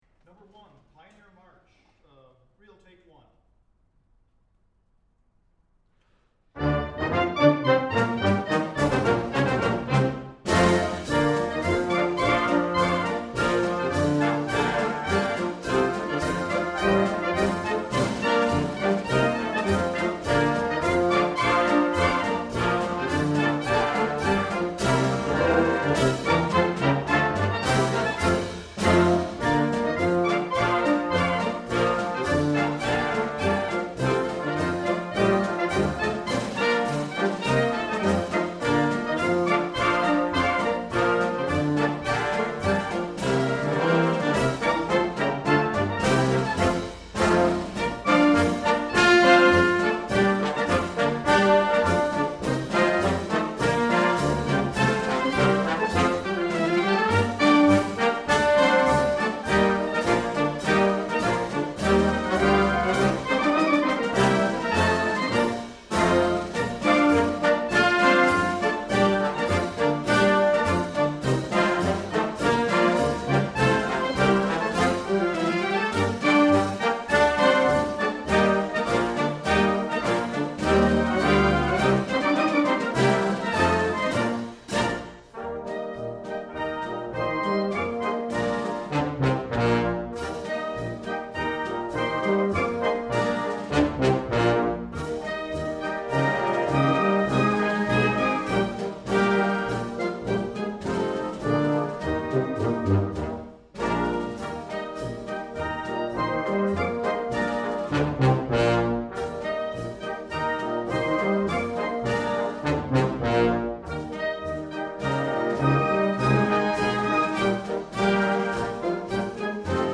These were sight read and recorded on August 7'th 2007.